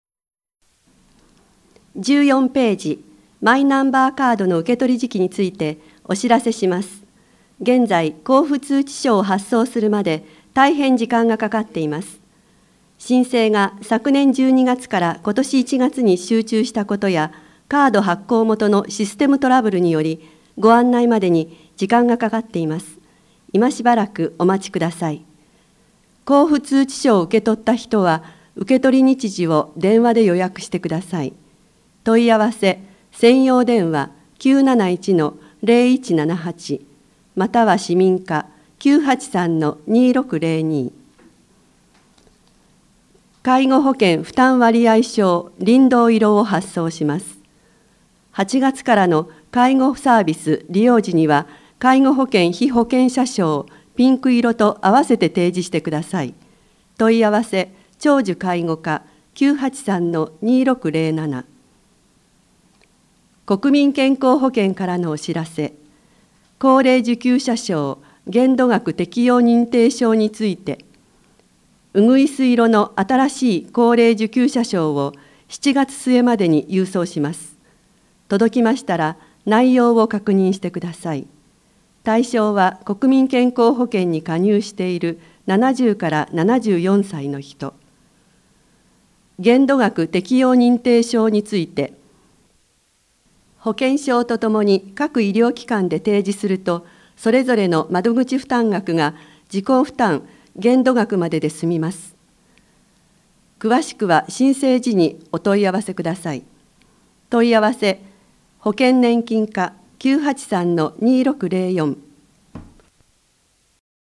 三島市社会福祉協議会で活動するボランティアグループ「やまなみ」は、視覚障害者への情報提供として「広報みしま」毎月１日号・１５日号を録音し、声の広報として送り届ける活動をしています。